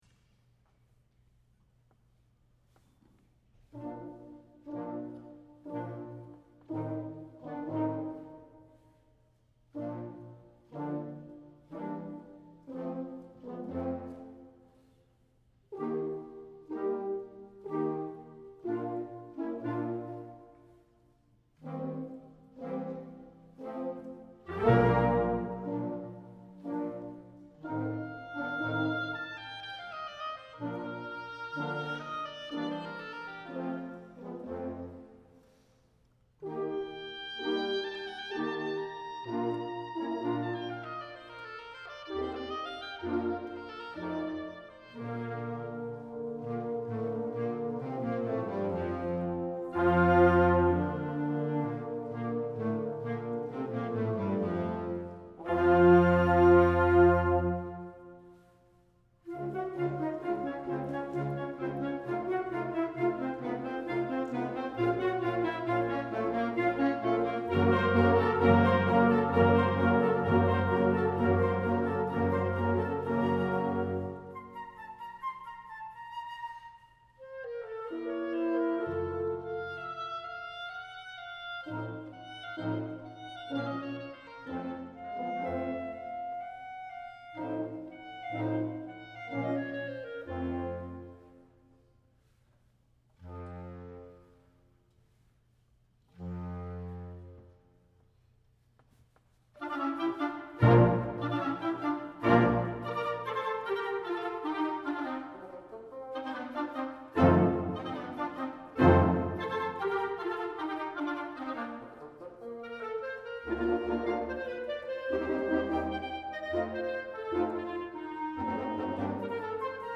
2017 Spring Concert Wind Ensemble — Liberty Band
2017 Spring Concert Wind Ensemble by Liberty HS Wind Ensemble 9:20 Circus Overture Liberty HS Wind Ensemble 8:21 Italian in Algiers Liberty HS Wind Ensemble 3:36 The Melody Shop Liberty HS Wind Ensemble